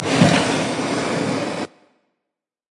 来自我的卧室的声音 " 硬币晃动2（冻结）2
描述：在Ableton中录制并略微修改的声音